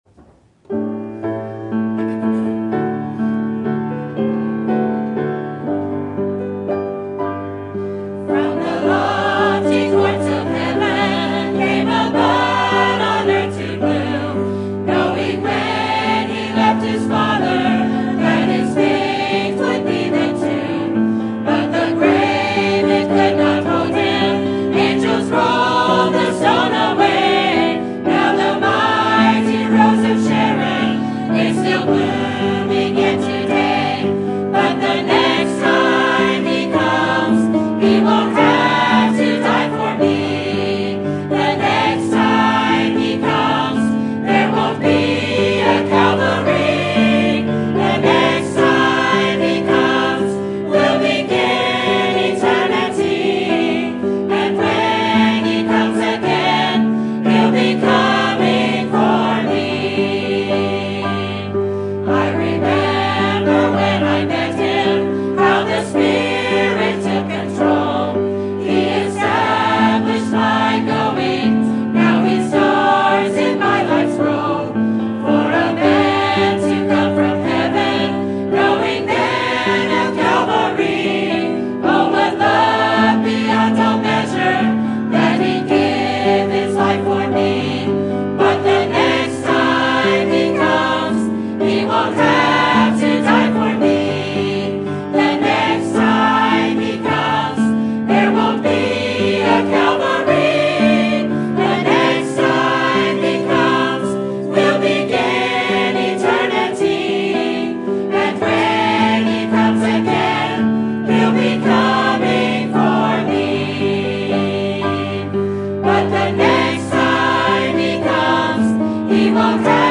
Sermon Topic: General Sermon Type: Service Sermon Audio: Sermon download: Download (23.11 MB) Sermon Tags: Ephesians Church Prayer Paul